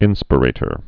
(ĭnspə-rātər)